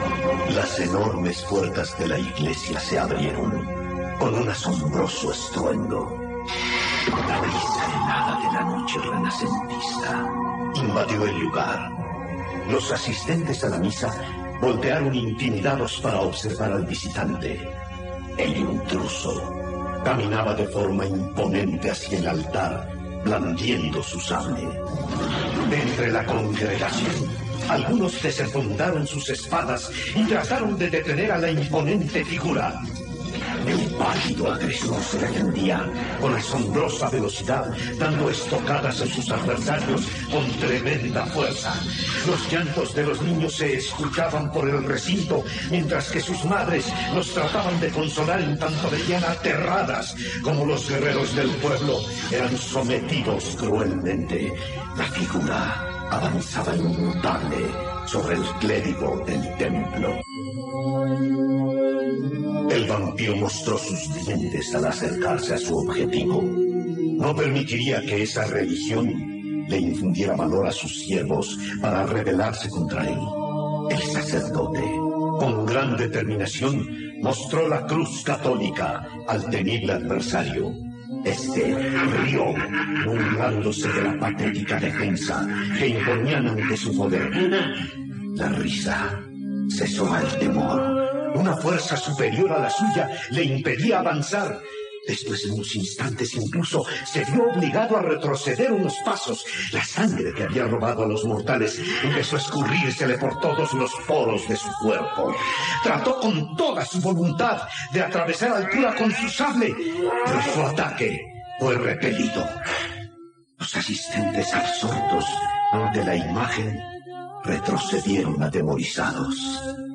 Relato – La Fe